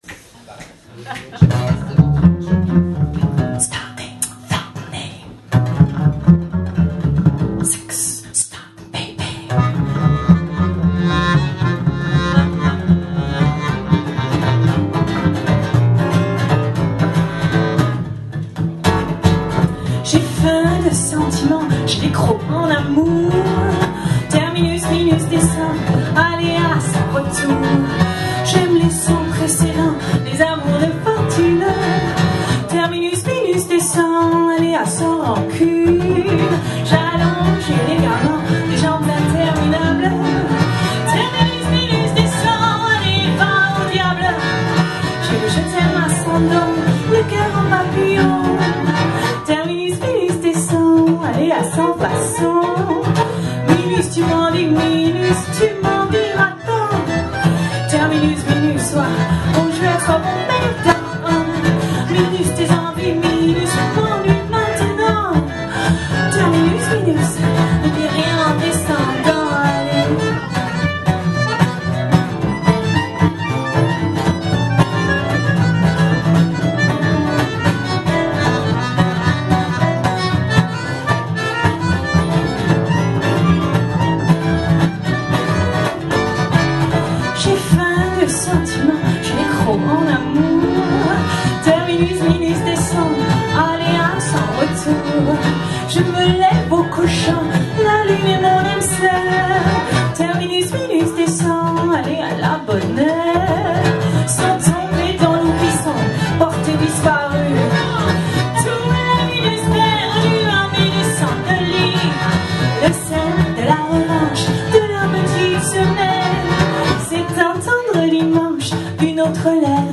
accordéon
violon
contrebasse